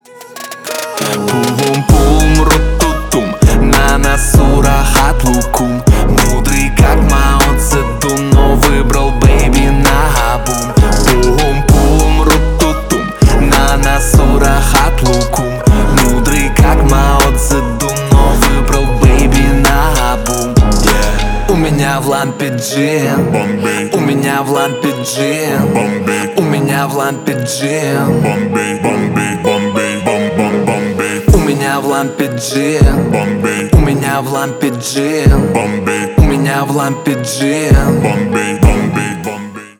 Рэп и Хип Хоп
весёлые